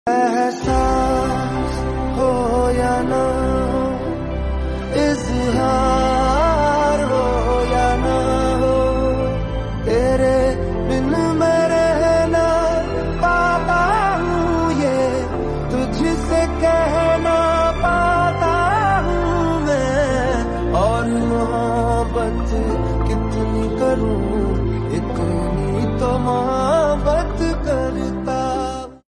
• Simple and Lofi sound
• Crisp and clear sound